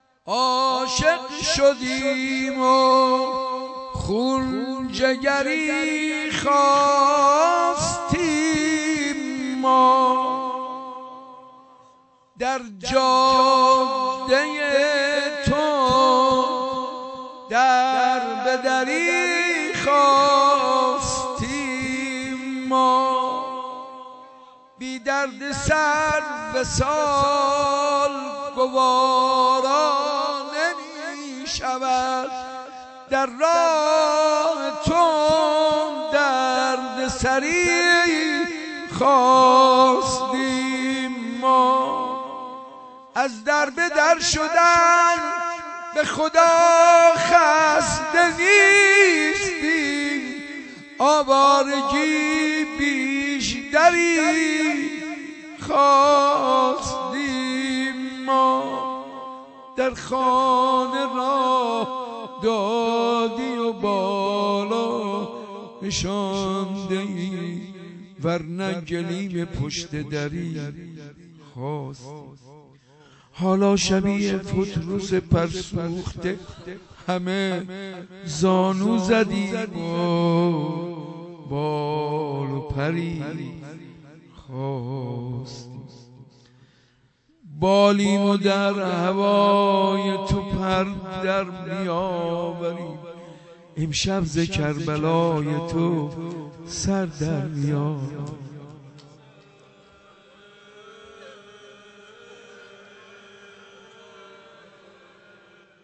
مدح امام حسین(ع)